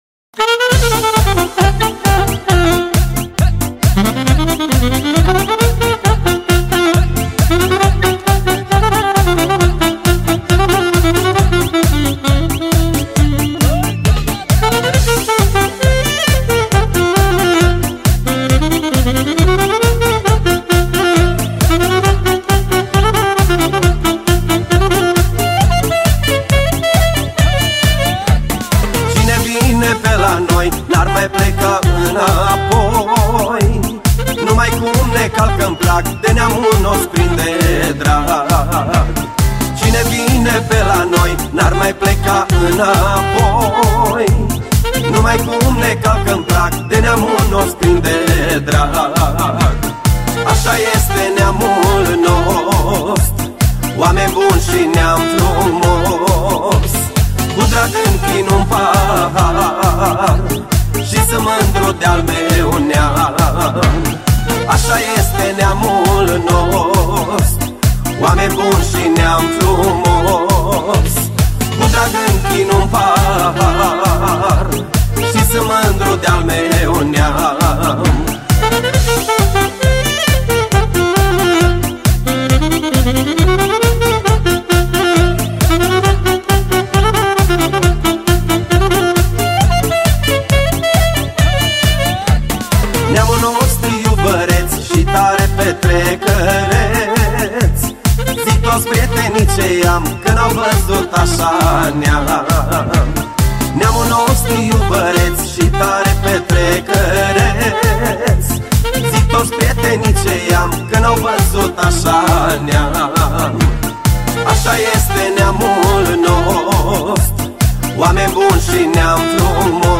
Data: 01.10.2024  Manele New-Live Hits: 0